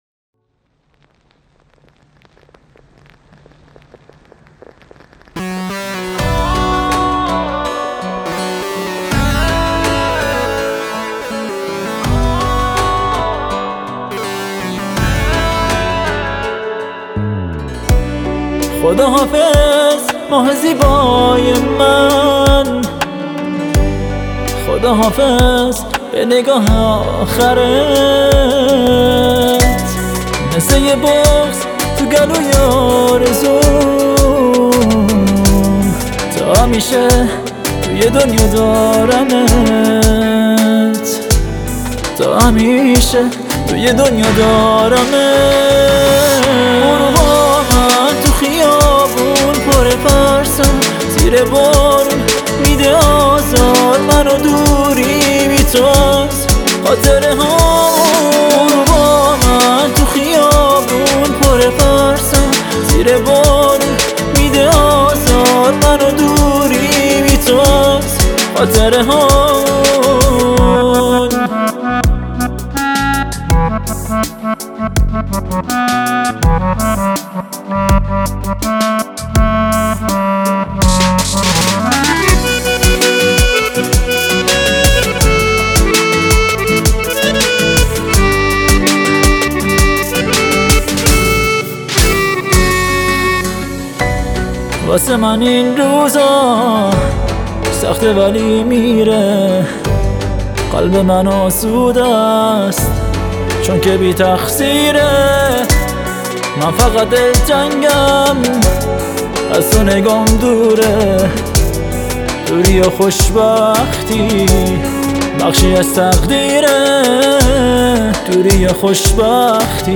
یک آهنگ احساسی است